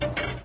shoot.ogg